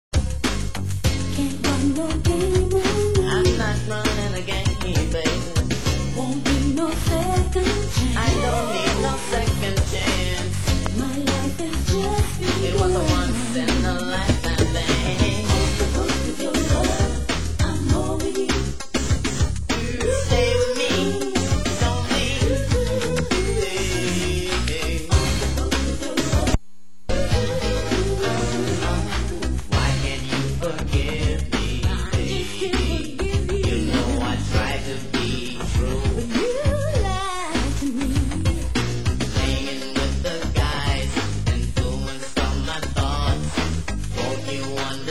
Format: Vinyl 12 Inch
Genre: US House